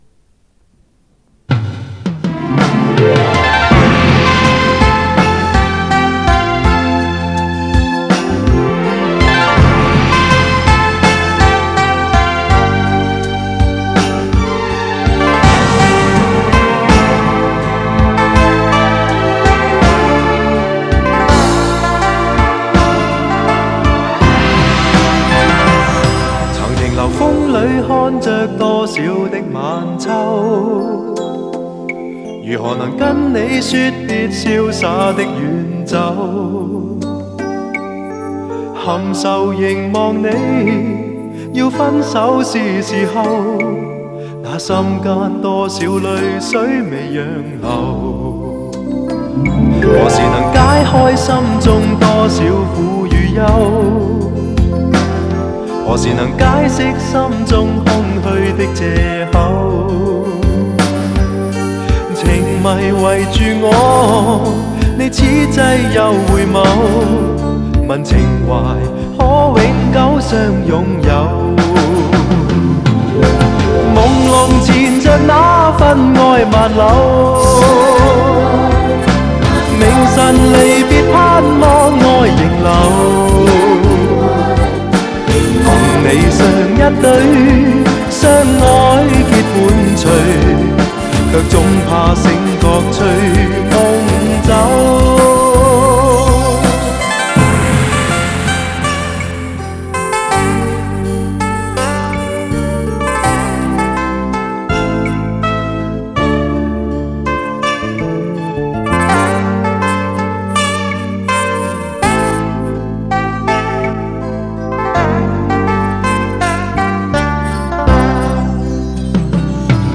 磁带数字化：2022-09-13
尤其是以粵语演唱的时候有一股明显的港味
作为粤语歌曲，它与香港流行曲有着相同的粤文化背景，曲调有着粤曲的韵味。